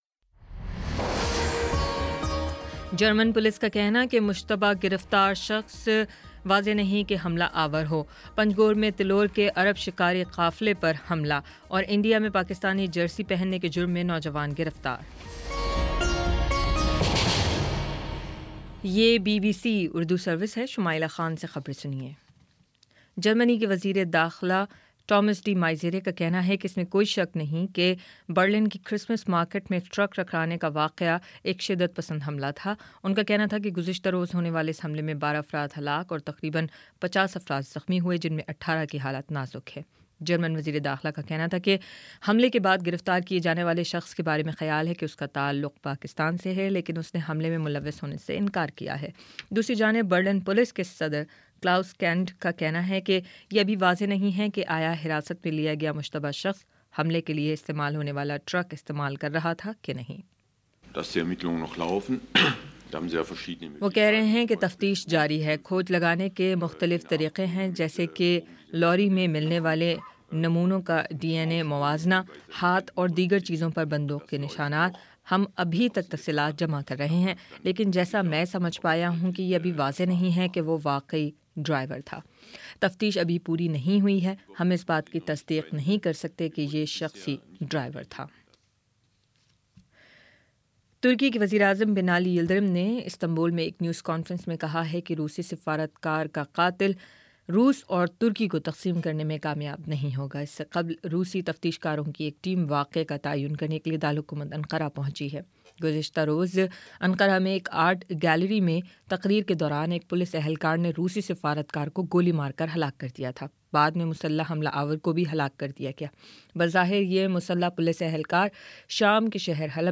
دسمبر 20 : شام سات بجے کا نیوز بُلیٹن